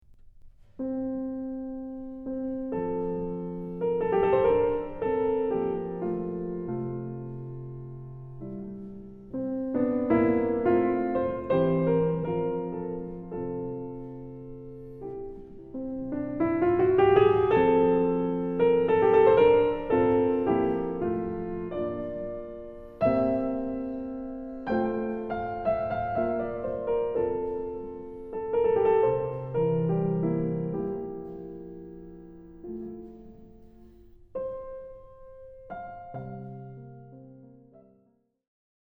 They are all in a Major key.